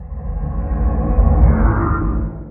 Minecraft Version Minecraft Version 1.21.4 Latest Release | Latest Snapshot 1.21.4 / assets / minecraft / sounds / mob / guardian / elder_idle3.ogg Compare With Compare With Latest Release | Latest Snapshot
elder_idle3.ogg